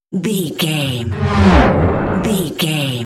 Sci fi big vehicle whoosh
Sound Effects
futuristic
whoosh
vehicle